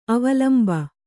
♪ avalmba